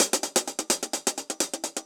UHH_AcoustiHatB_128-02.wav